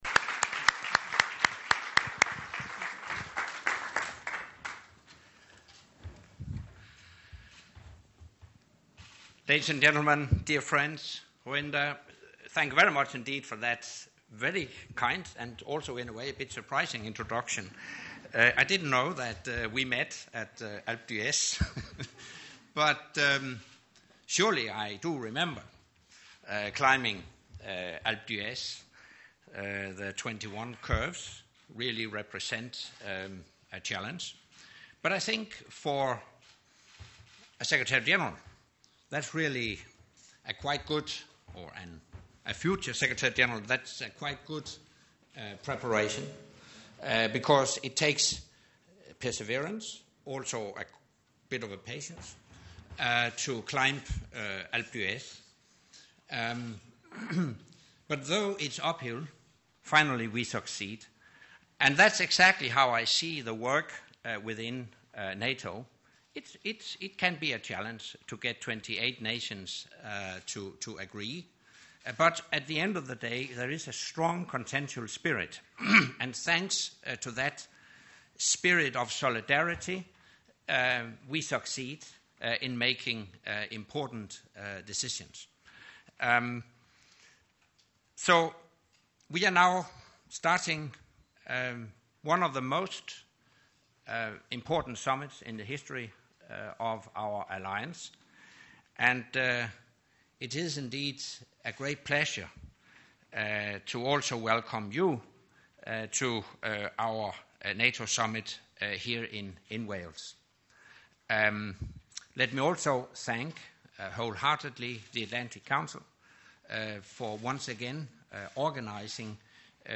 The NATO Secretary General Anders Fogh Rasmussen stressed on Thursday (4 September 2014) that the Wales summit will forge a stronger NATO for a more complex and chaotic world, during an address to the Atlantic Council of the United States "Future Leaders Summit" in Newport, Wales, ahead of the start of the leader's gathering.